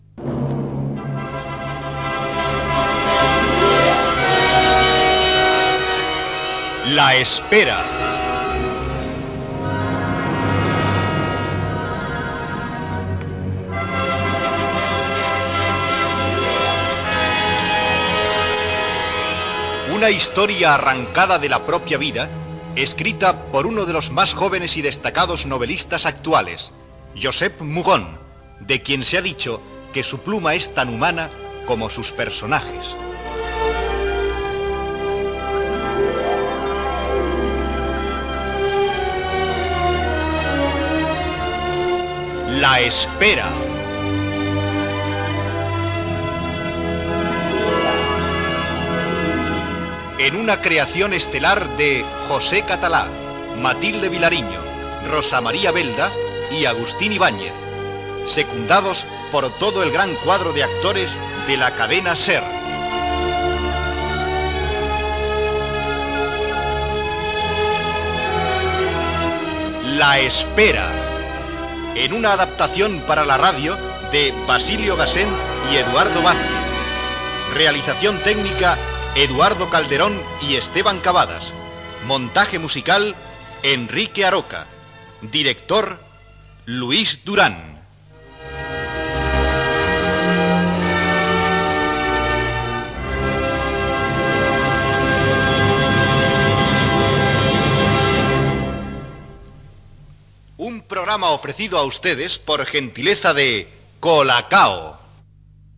Careta del serial amb els noms de l'equip
Ficció